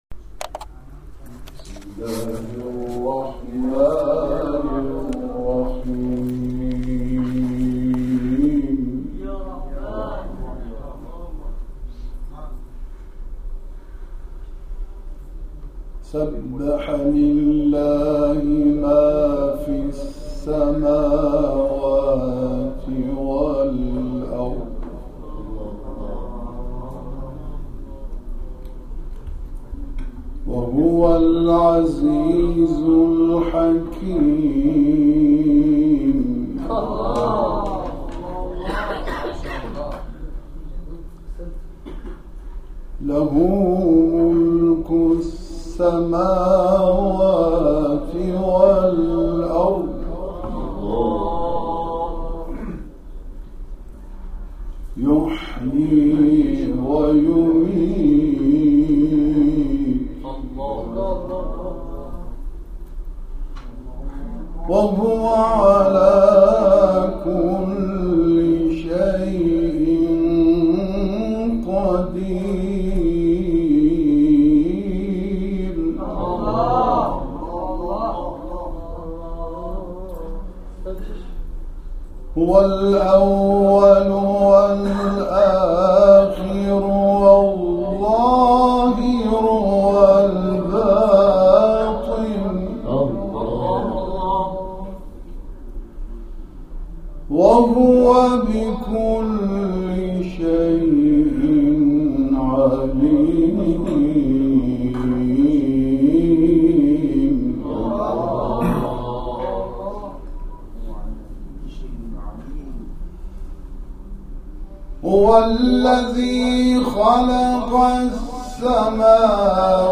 جلسه قرآنی صمیمی در دل پارک شریعتی+صوت و عکس
در پایان این جلسه صمیمی، جمع خوانی صفحات ۵۳۷ و ۵۳۸ از ادامه آیات درس جلسه، صورت گرفت.